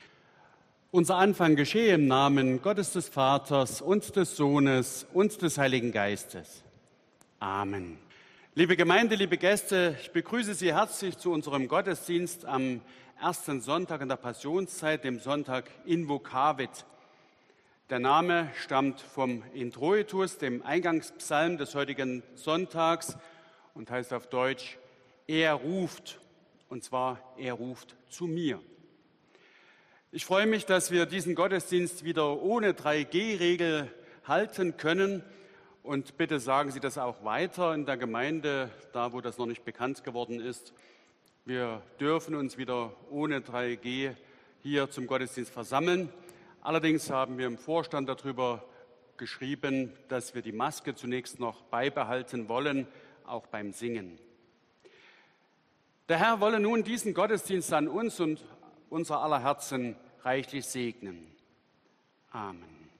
Audiomitschnitt unseres Gottesdienstes vom Sonntag Invokavit 2022.